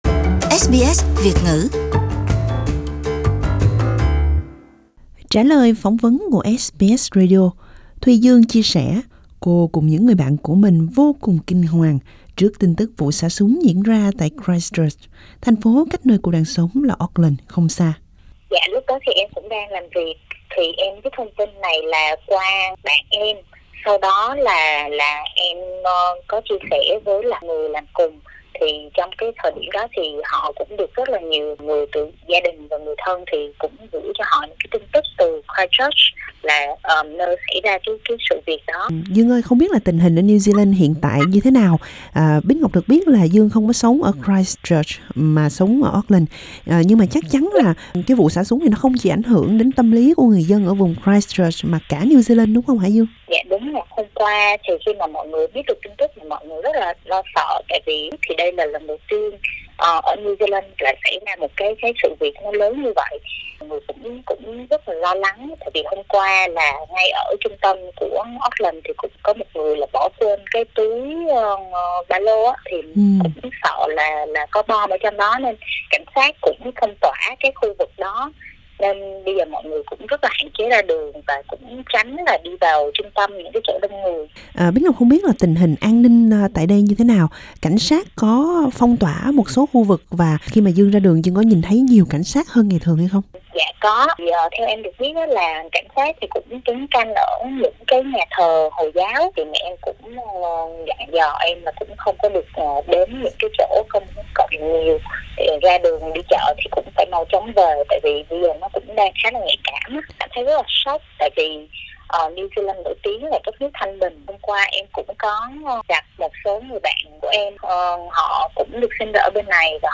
Trả lời phỏng vấn của SBS